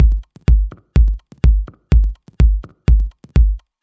• house - techno beat passage - Gm - 125 - 3.wav
A loop that can help you boost your production workflow, nicely arranged electronic percussion, ready to utilize and royalty free.
house_-_techno_beat_passage_-_Gm_-_125_-_3_2es.wav